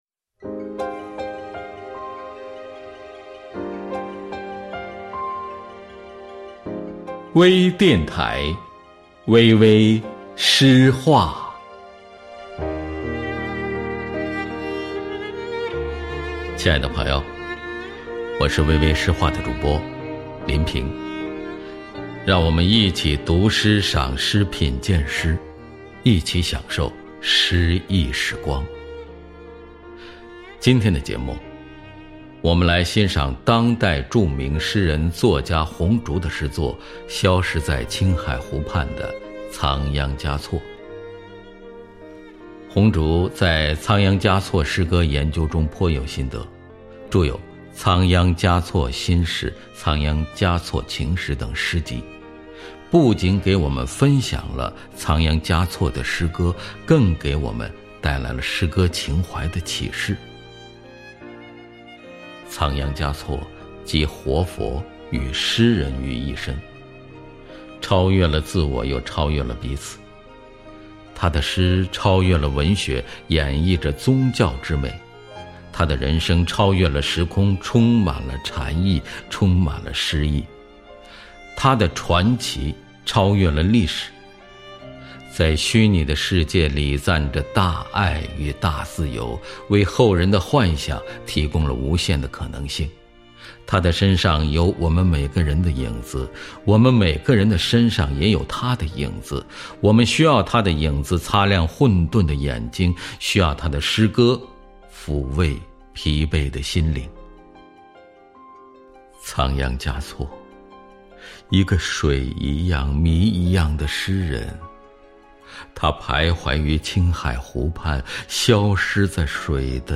多彩美文  专业诵读
朗诵者/配乐